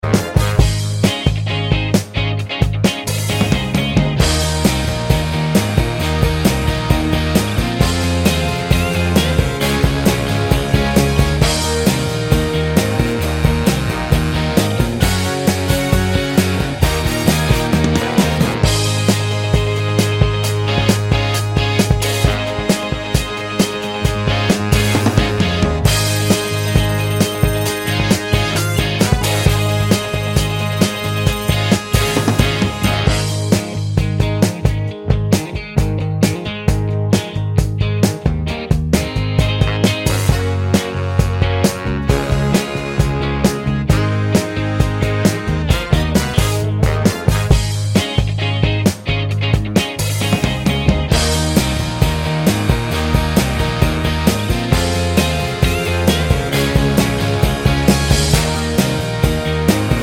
Minus Sax Solo Pop (1970s) 3:38 Buy £1.50